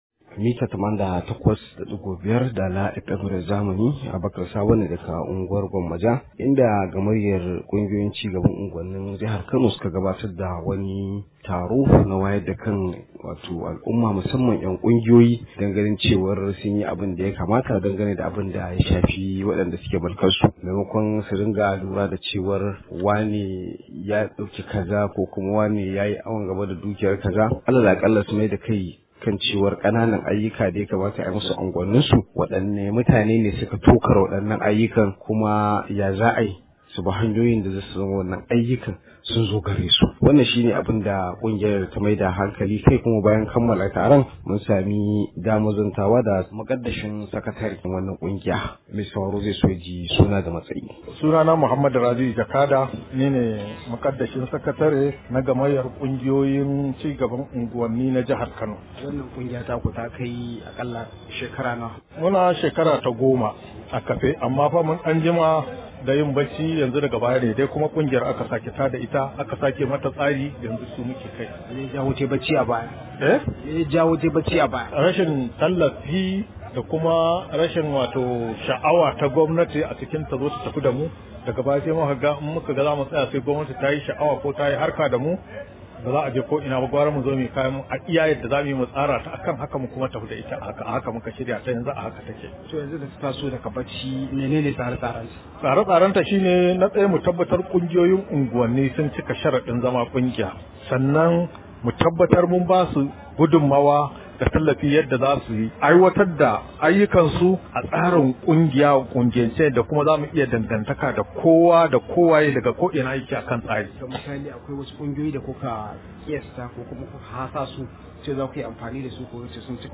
Rahoto: Za mu bibiyi dalilan makalewar kananan ayyukan unguwanni – Gamayyar kungiyoyi